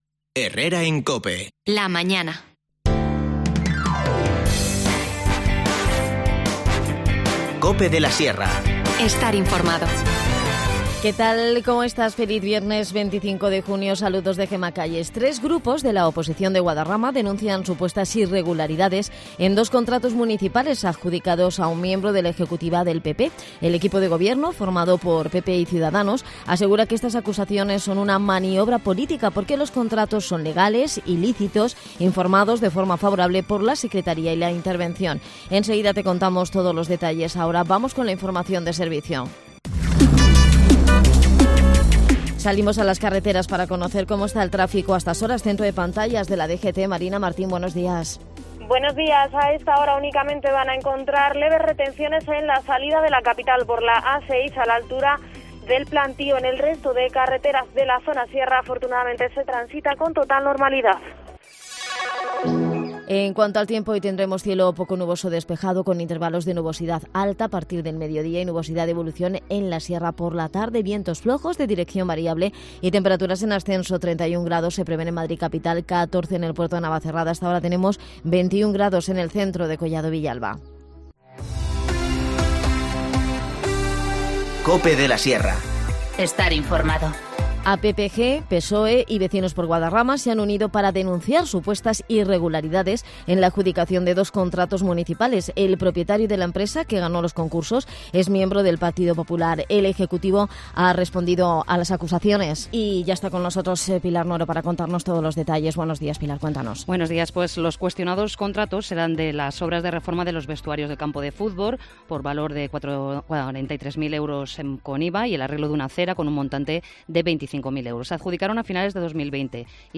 Todos los detalles nos lo ha contado uno de los comerciantes que participa en esta iniciativa.